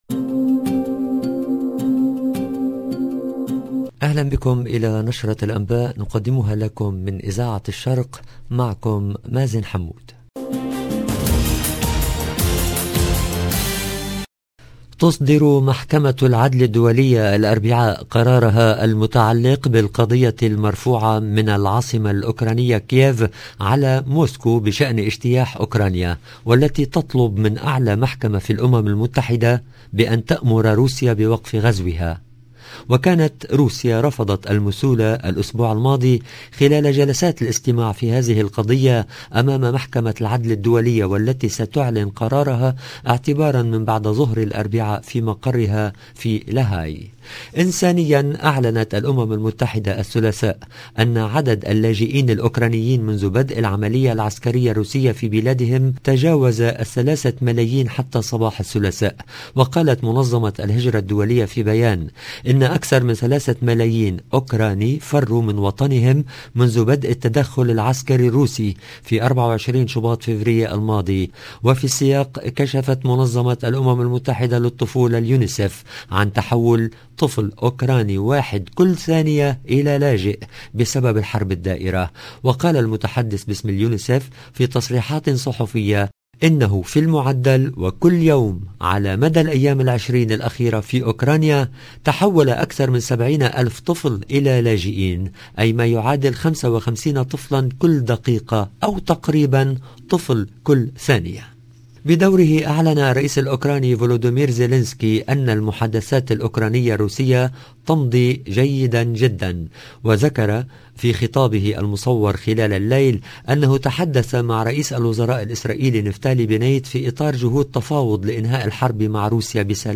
LE JOURNAL EN LANGUE ARABE DU SOIR DU 15/03/22